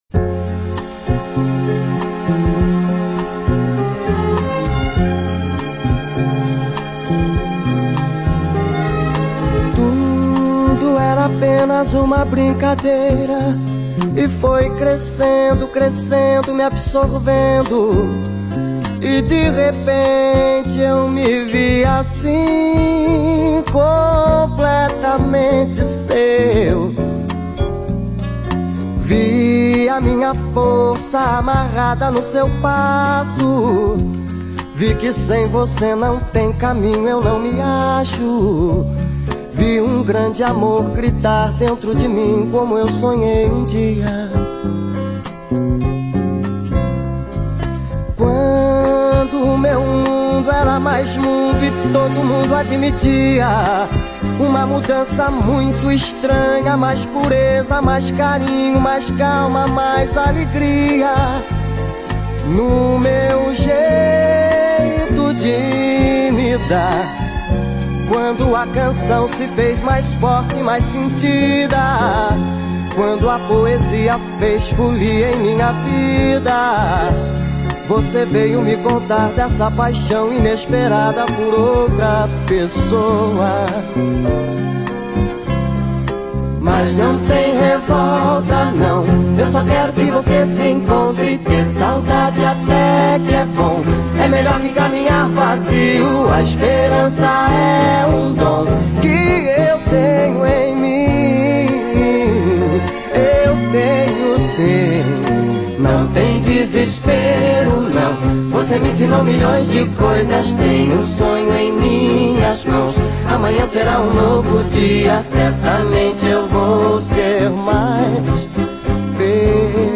O BAÚ DA MÚSICA  PRESENTE MAIS UMA BOM SUCESSO DA NOSSA MPB